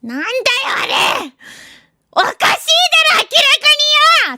Worms speechbanks
Oinutter.wav